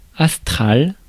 Ääntäminen
Synonyymit sidéral Ääntäminen France: IPA: /as.tʁal/ Haettu sana löytyi näillä lähdekielillä: ranska Käännös Ääninäyte Adjektiivit 1. astral 2. starry US 3. stellar 4. sideral Suku: m .